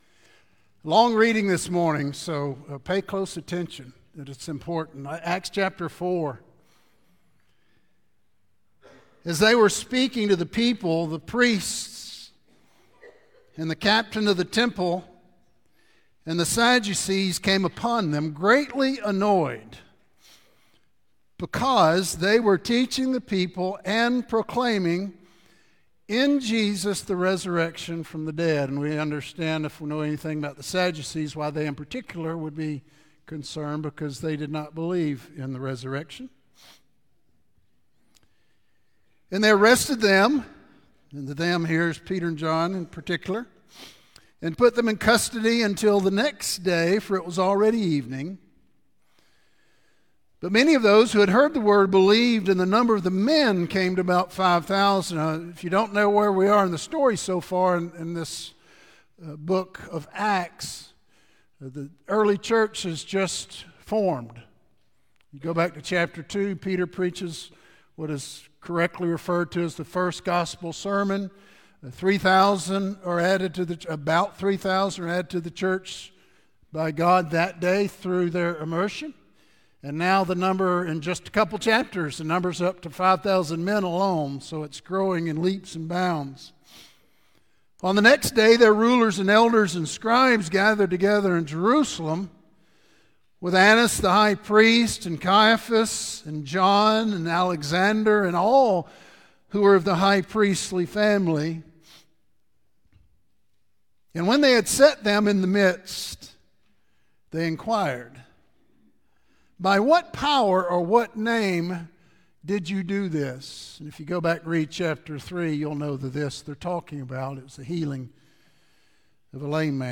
Sermons | West Metro Church of Christ